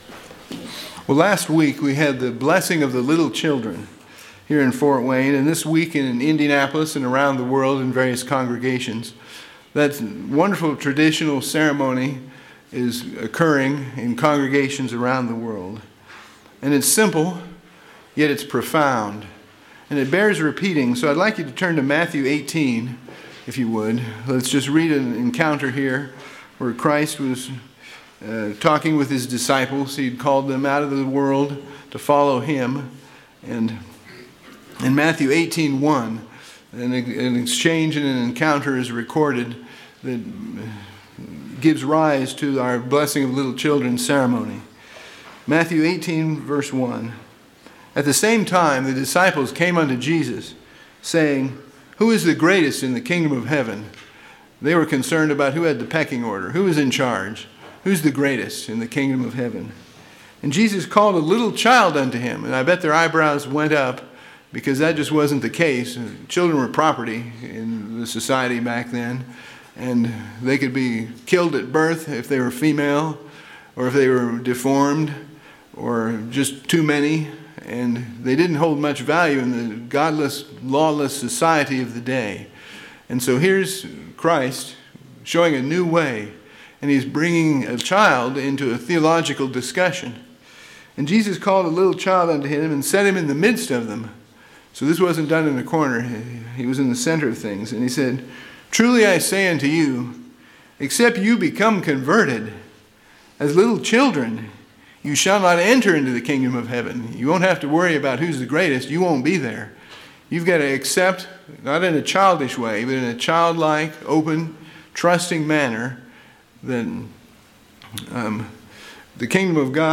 This sermon covers scriptures that show God is raising up children destined for success.
Given in Ft. Wayne, IN